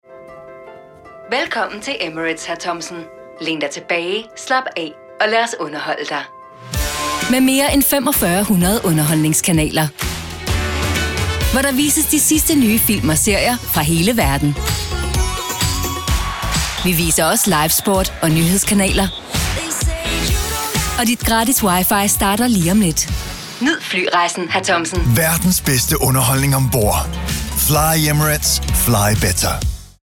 Sprechprobe: Werbung (Muttersprache):
Commercials